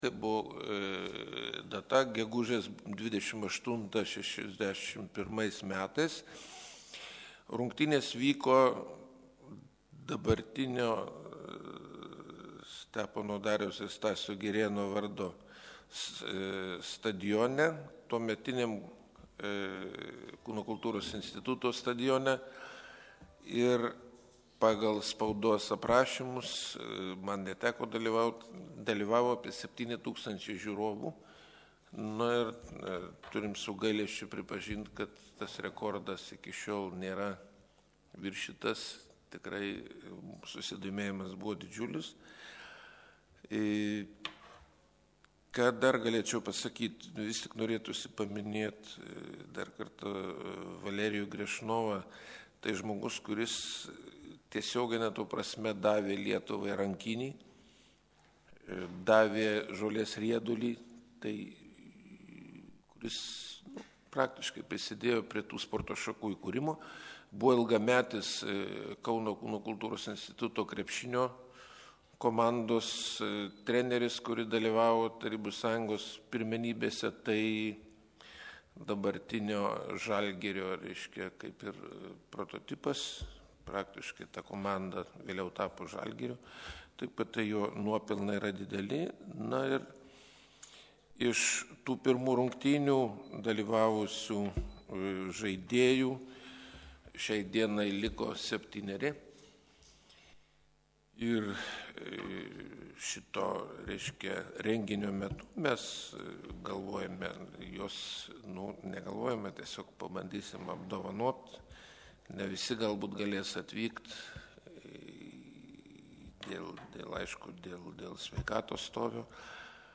Spaudos konferencijos garso �ra�as...
konferencija.mp3